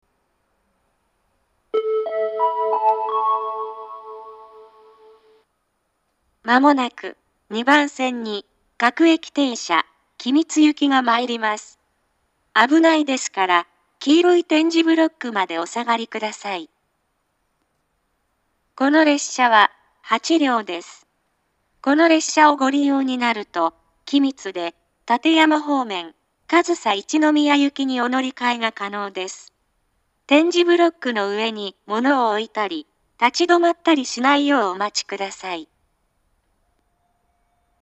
２番線接近放送
自動放送は合成音声です。